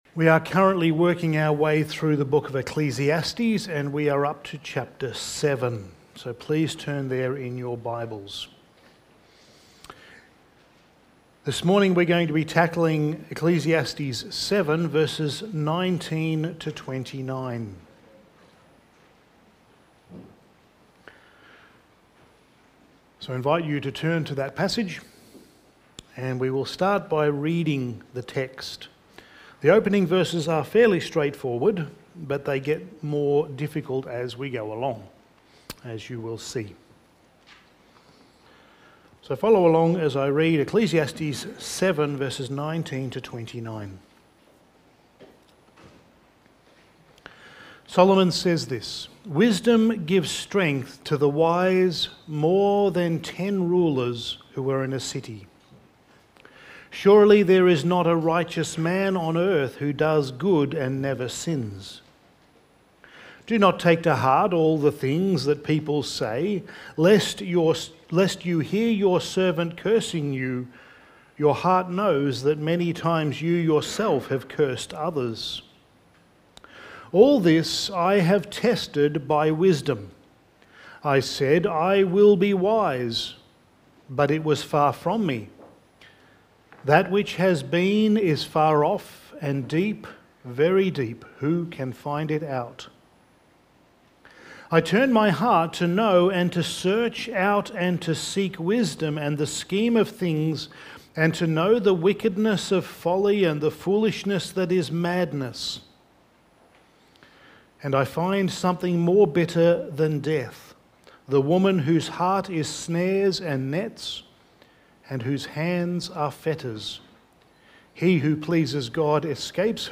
Passage: Ecclesiastes 7:19-29 Service Type: Sunday Morning